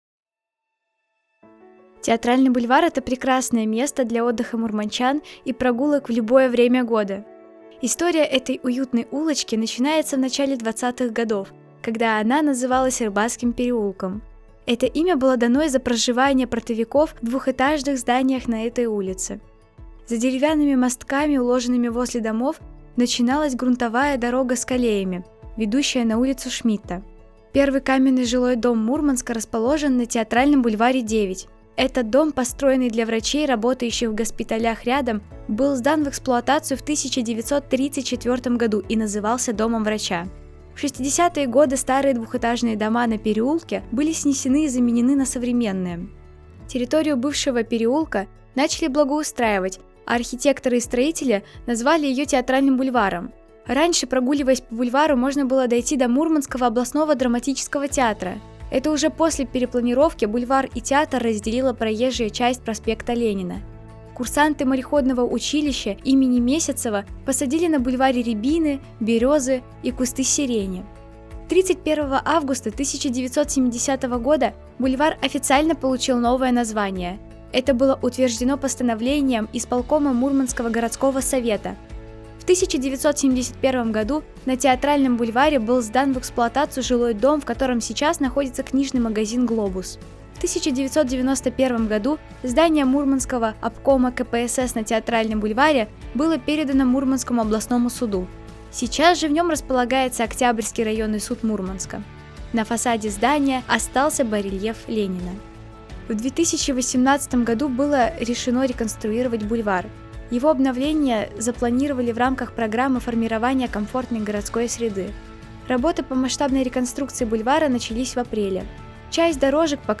Предлагаем послушать аудиоэкскурсию о театральном бульваре, расположенном недалеко от Мурманского областного драматического театра